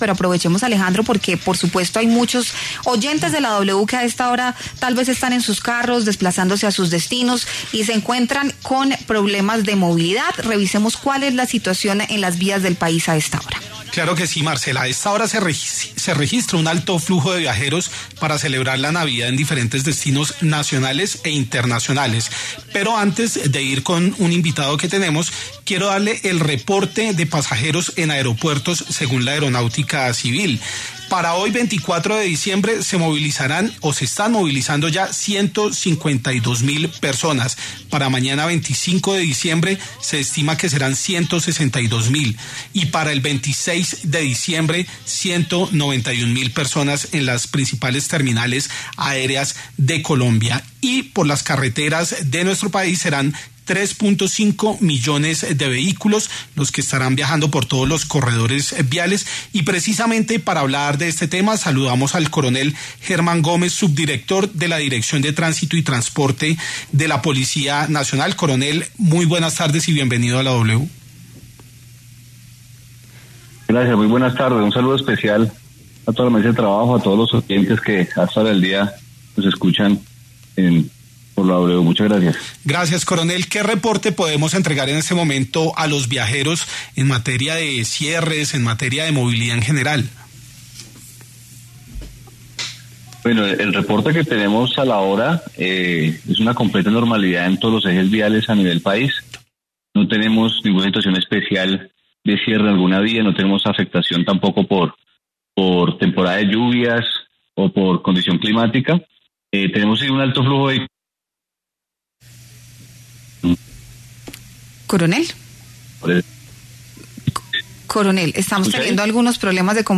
El coronel Germán Gómez, subdirector de la Dirección de Tránsito y Transporte de la Policía Nacional, dio en La W algunas recomendaciones a la hora de viajar por tierra en estas festividades decembrinas.